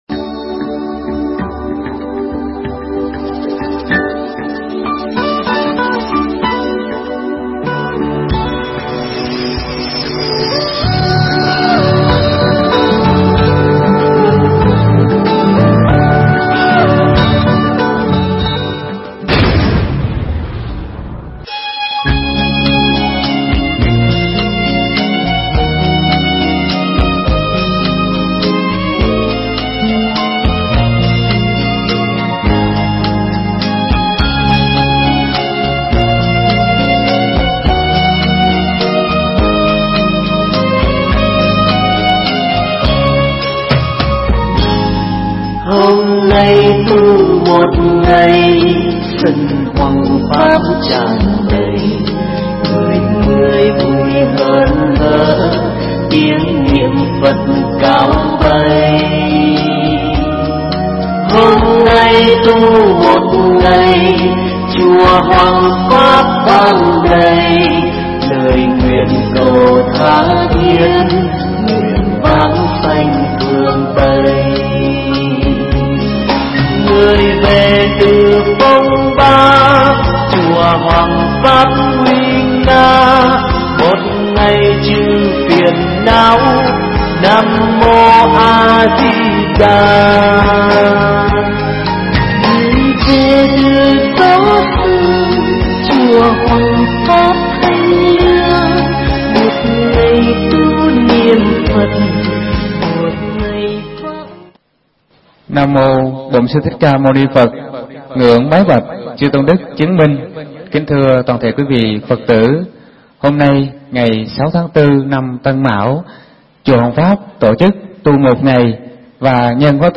Mp3 Thuyết Giảng Ánh sáng Phật pháp kỳ 29